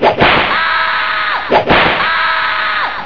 whip.wav